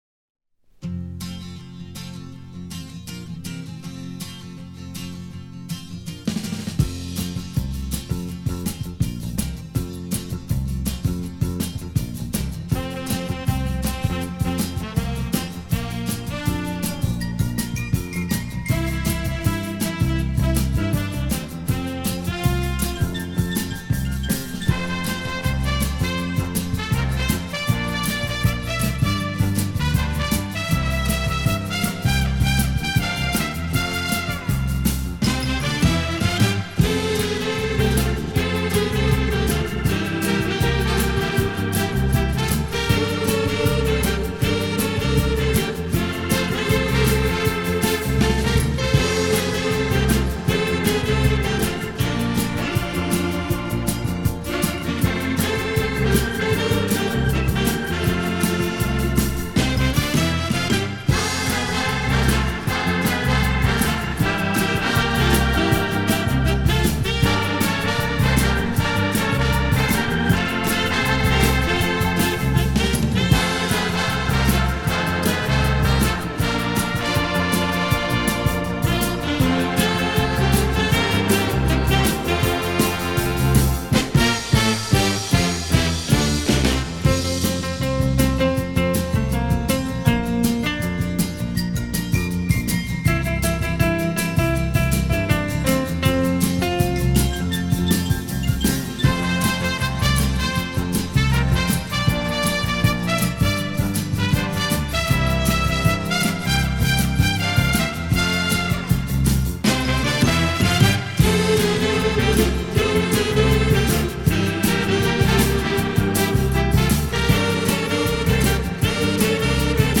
Я подредактировал трек (треск, провалы, частотка, моно):